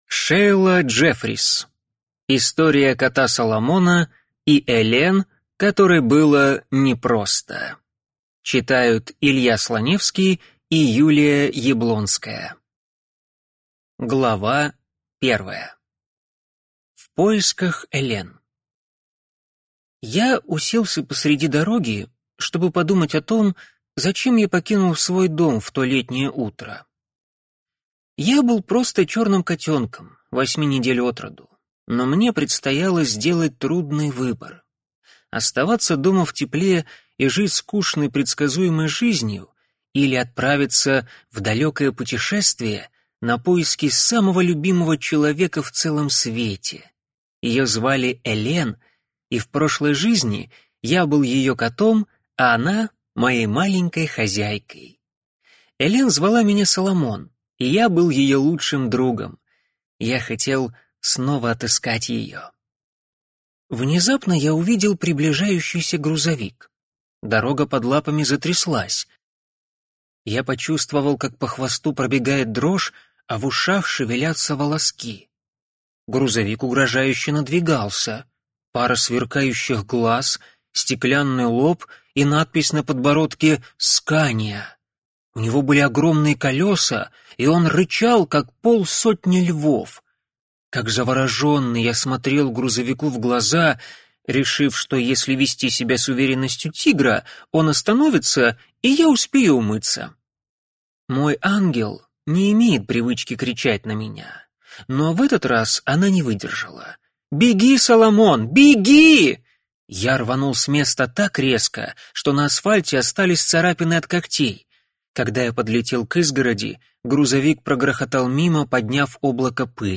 Аудиокнига История кота Соломона и Элен, которой было непросто | Библиотека аудиокниг